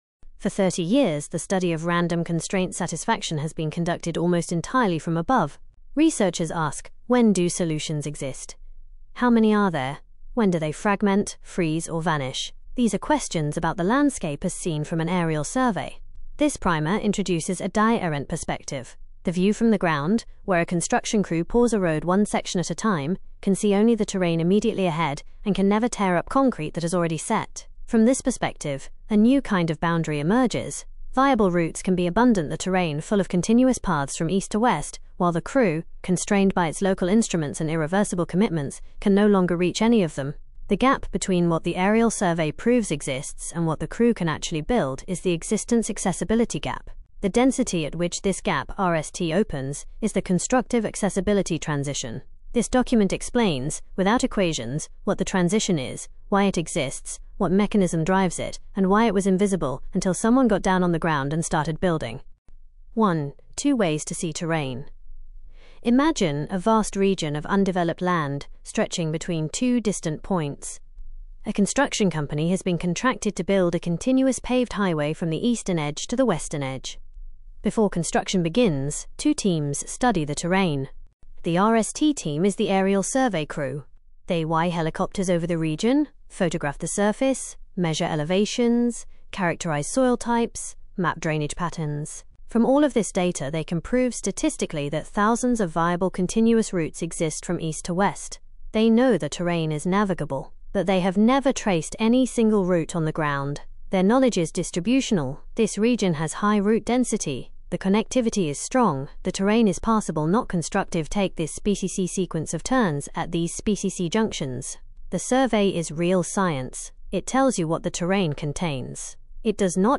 spoken narration